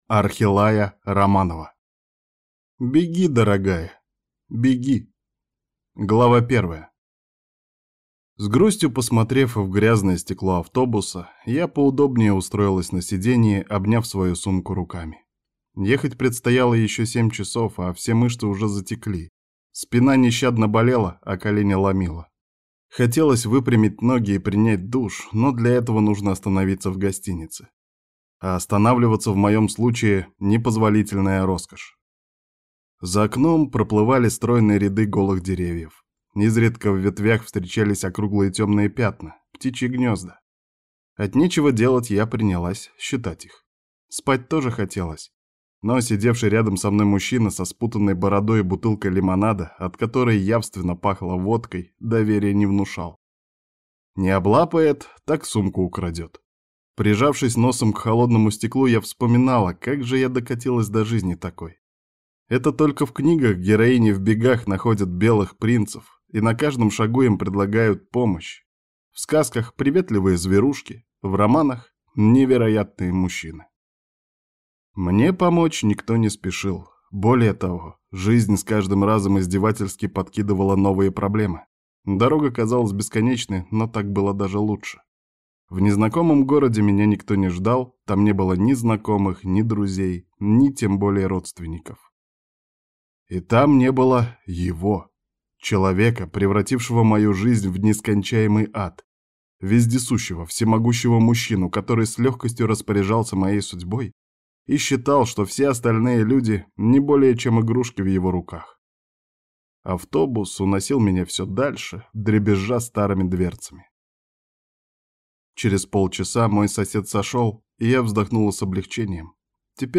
Аудиокнига Беги, дорогая, беги | Библиотека аудиокниг